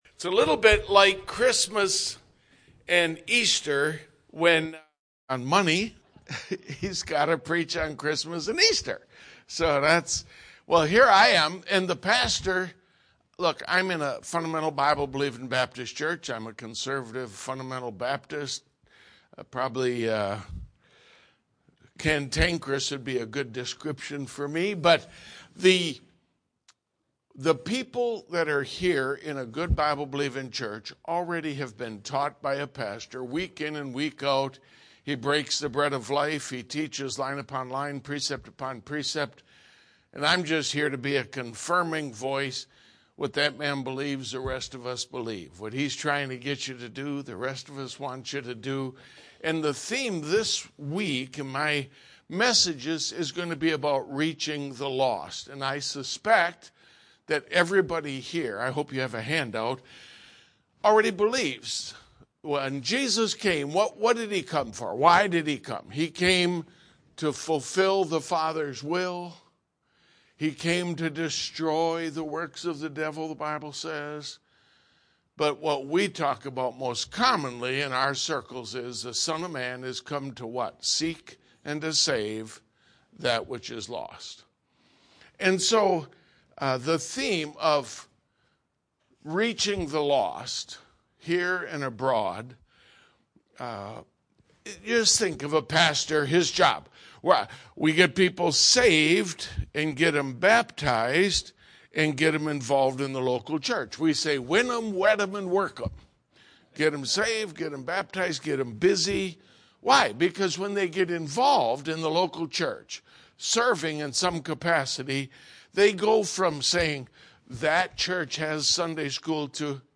This is a simple lesson on how to give your testimony when you are witnessing to a lost person about the Lord Jesus Christ.